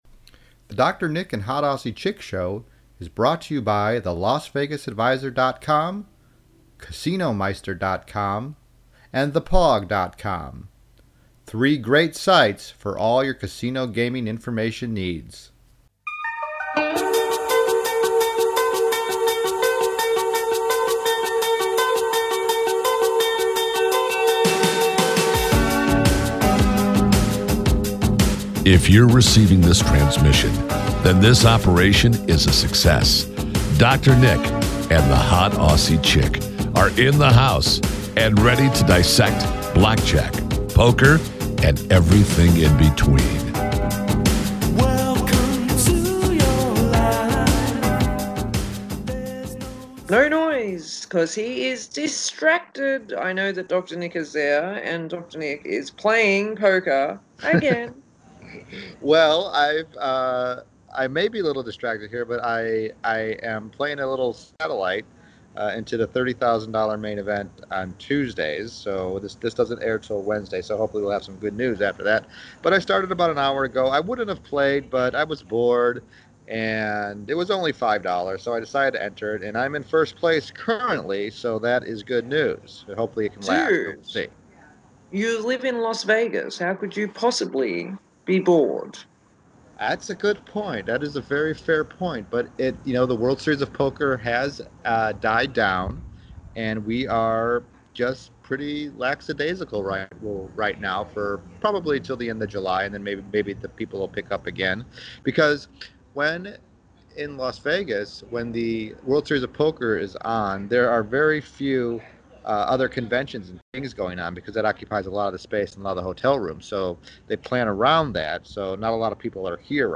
This show should not be listened to by those sensitive to the F-Bomb or to casino employees that take offense to being tricked.